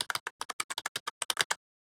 Keyboard_1_med_tapping_02